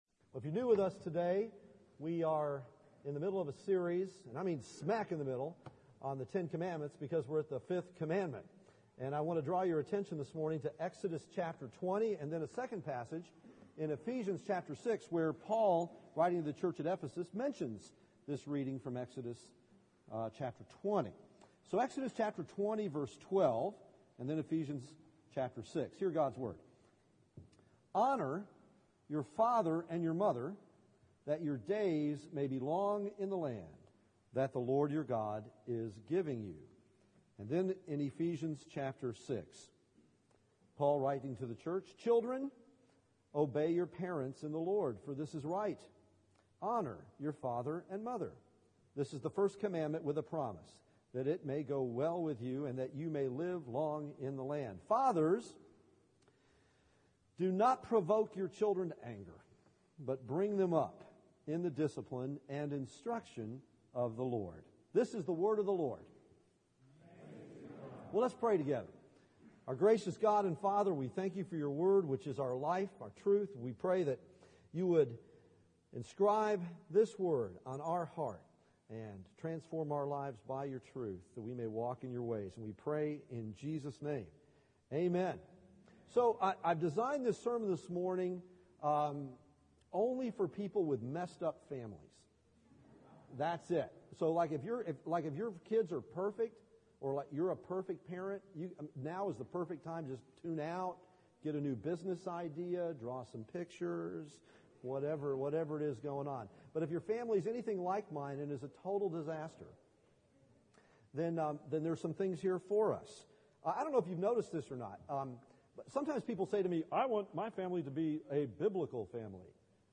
The Ten Commandments Passage: Exodus 20:12; Ephesians 6:1-4 Service Type: Sunday Morning « Jesus and Pilate